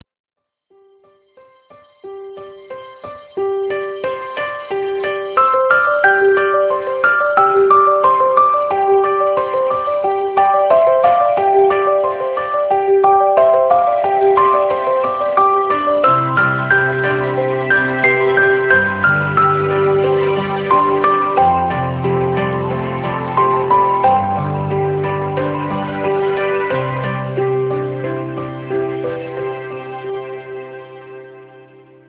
HoldingMessage3.amr